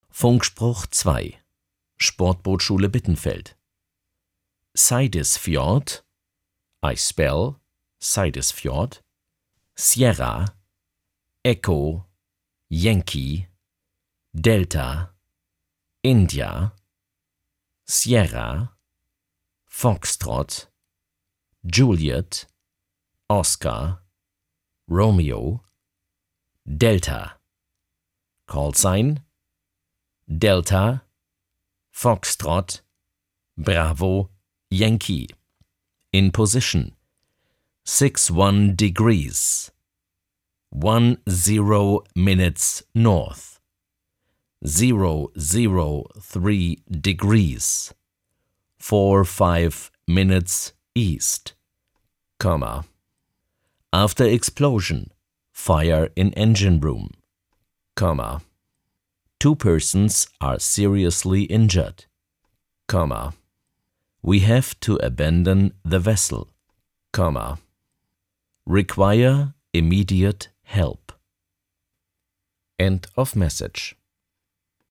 Funkspruch-2.mp3